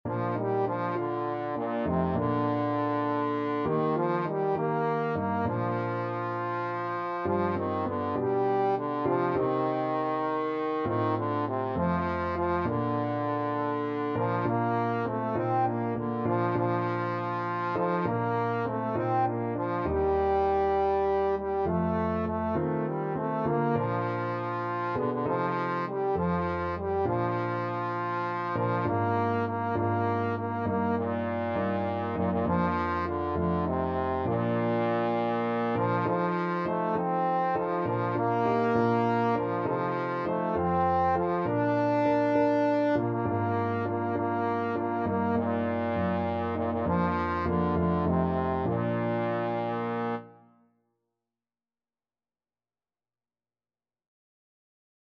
Christian Christian Trombone Sheet Music His Eye Is on the Sparrow
Trombone
Bb major (Sounding Pitch) (View more Bb major Music for Trombone )
6/8 (View more 6/8 Music)